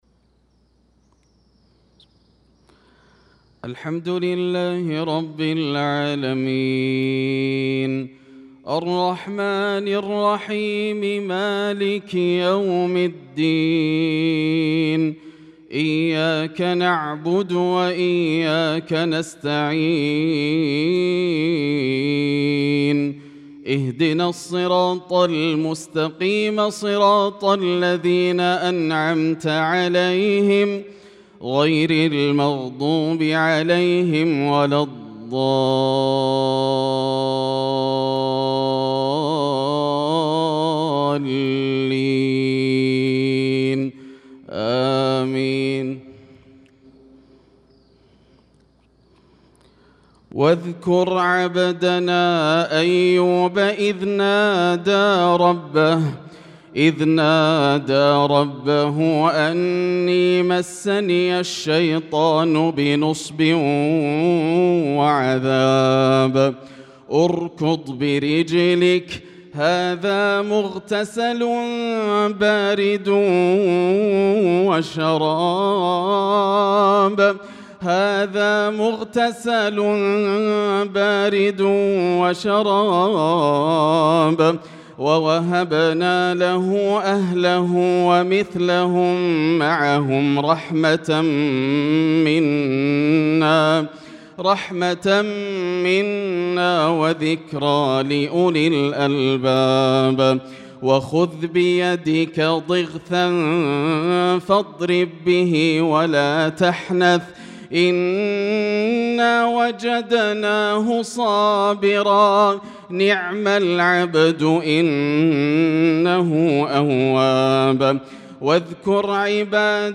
صلاة الفجر للقارئ ياسر الدوسري 6 ذو القعدة 1445 هـ